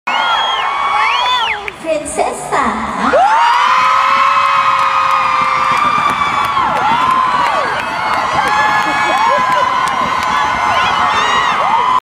The Way the Crowd went sound effects free download